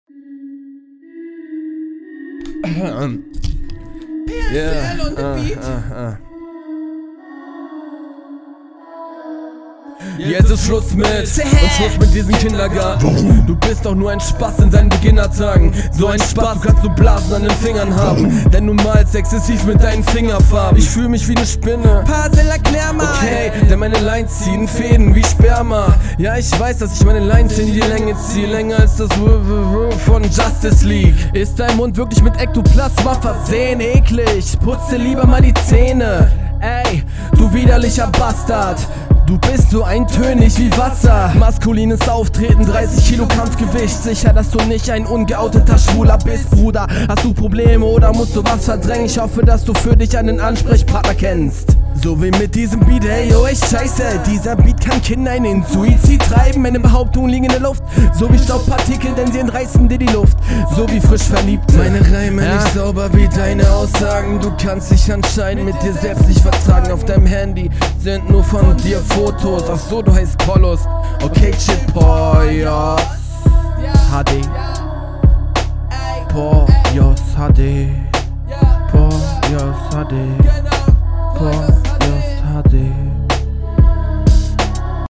Die Schmatzgeräusche waren sehr eklig.